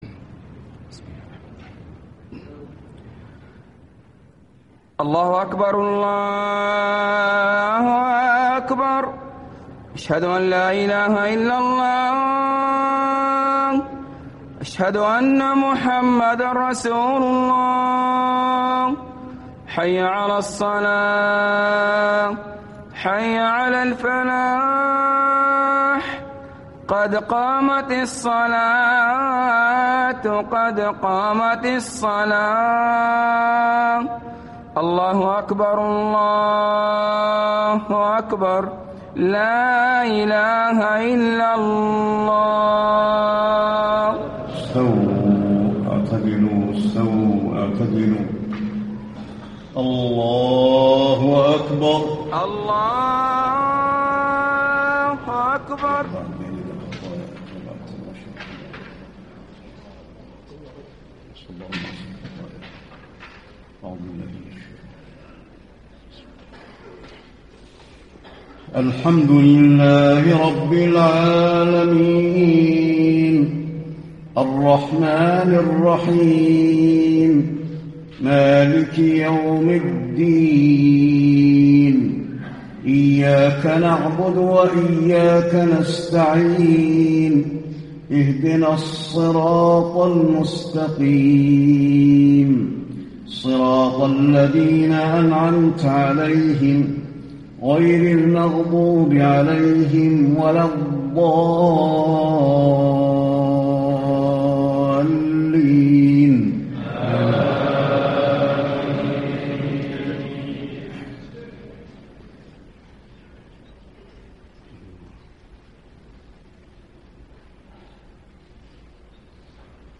صلاة الجمعة 3-2-1435 سورتي الأعلى و الغاشية > 1435 🕌 > الفروض - تلاوات الحرمين